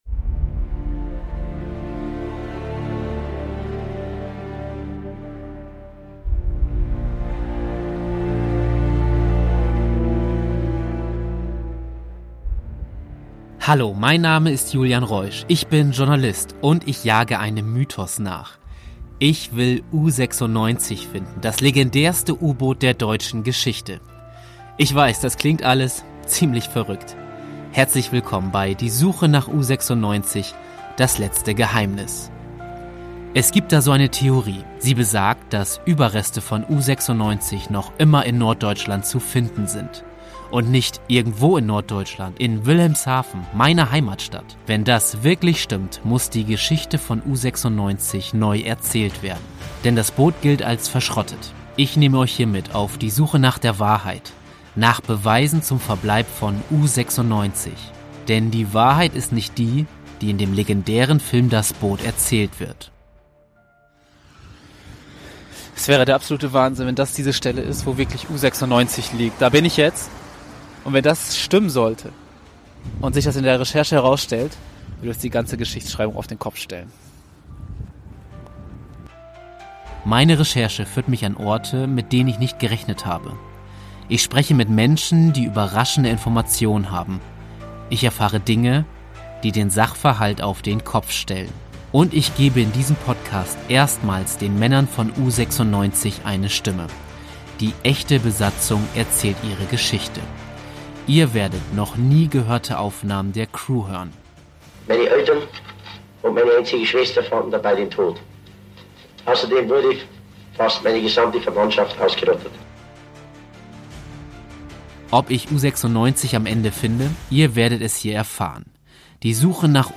In diesem Podcast bekommen erstmals auch die Männer von U96 eine Stimme. Die echte Besatzung erzählt persönliche Geschichten. Geschichten über tiefe Trauer, unmenschliche Verhöre und ganz alltägliche Probleme. Diese noch nie veröffentlichten Aufnahmen gehen unter die Haut.